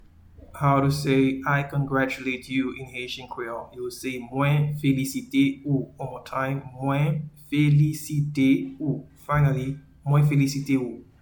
Pronunciation and Transcript:
I-congratulate-you-in-Haitian-Creole-Mwen-felisite-ou.mp3